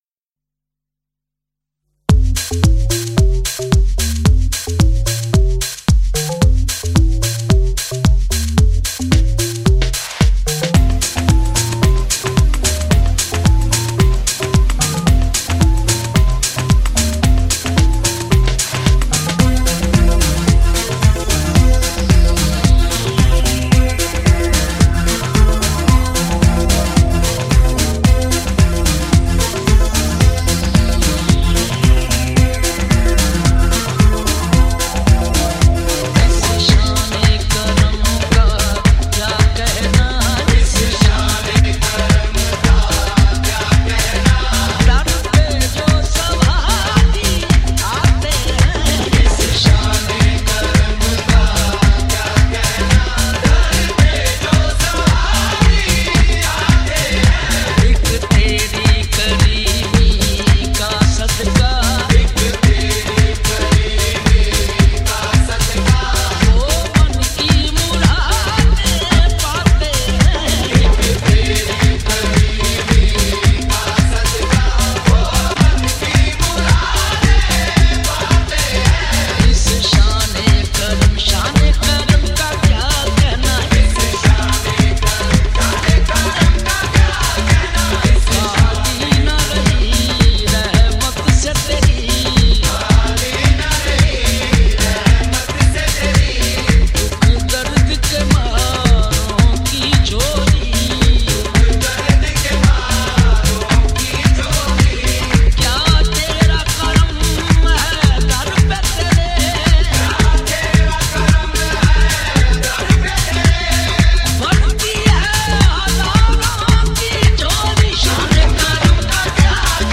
HomeMp3 Audio Songs > Qawwalis > 9 Classic Super Hit Remix